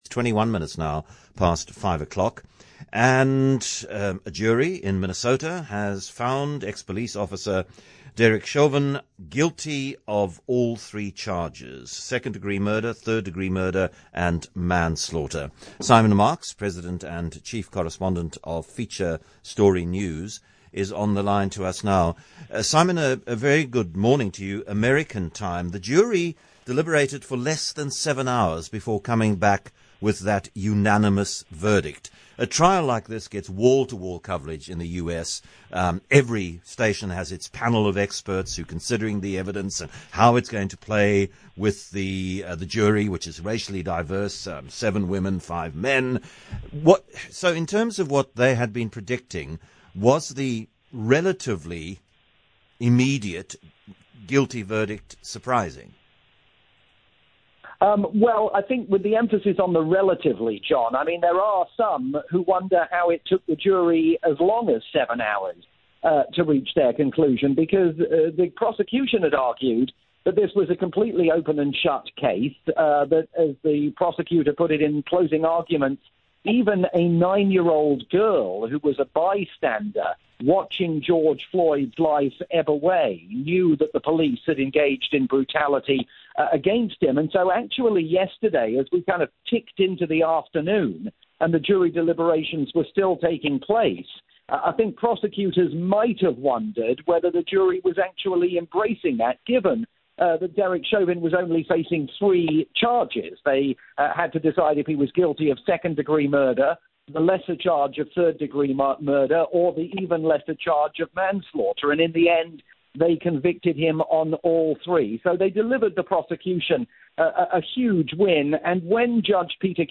live report for Capetalk Radio in Cape Town, South Africa